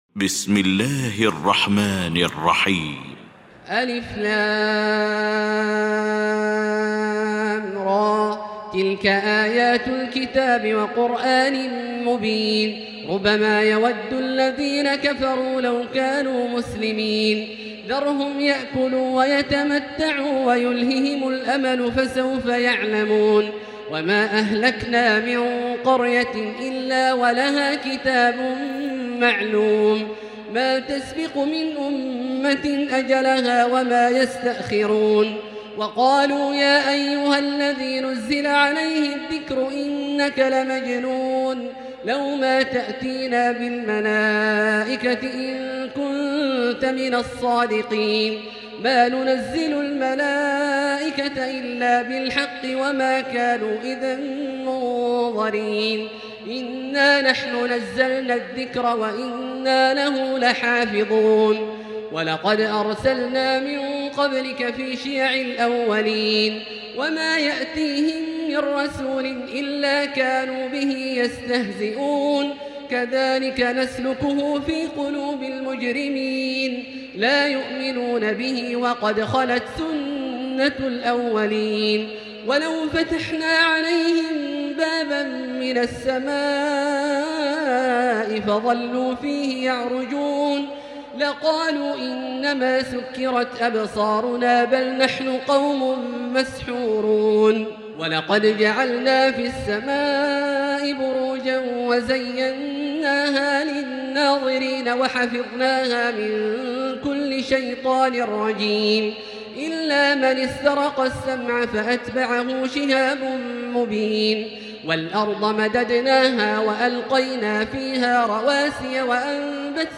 المكان: المسجد الحرام الشيخ: فضيلة الشيخ عبدالله الجهني فضيلة الشيخ عبدالله الجهني فضيلة الشيخ ماهر المعيقلي الحجر